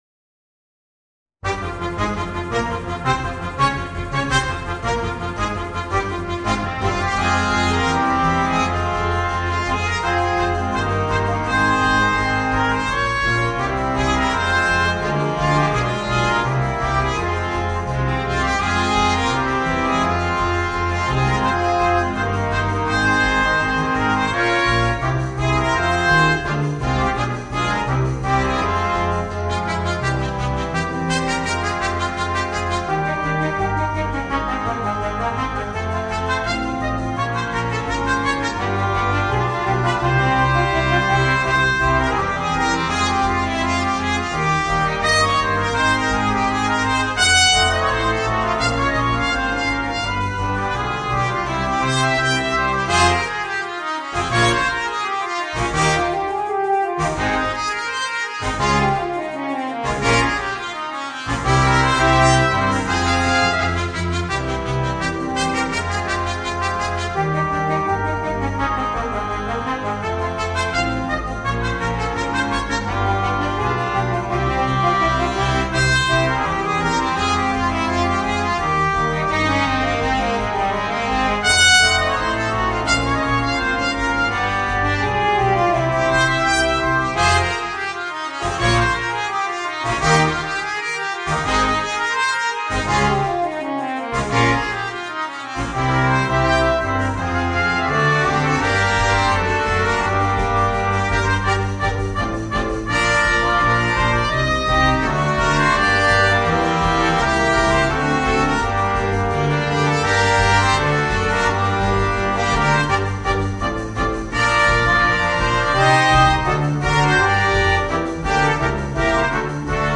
Voicing: Brass Band